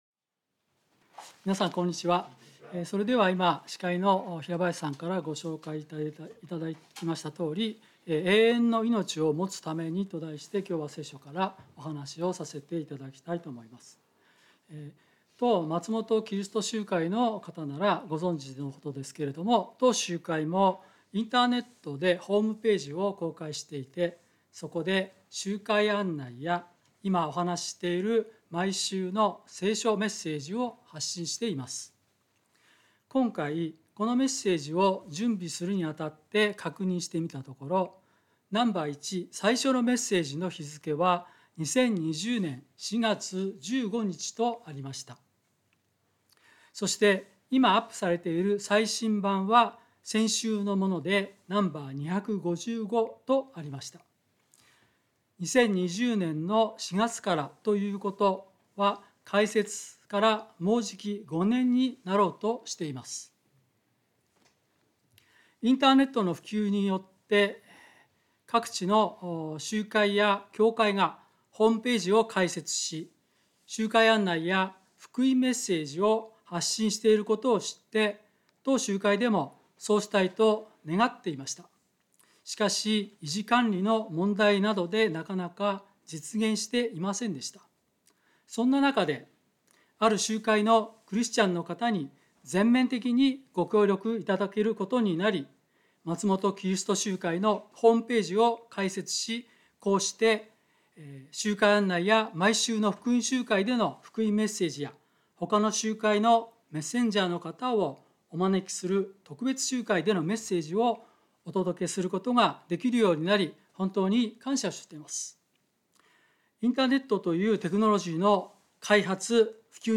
聖書メッセージ No.256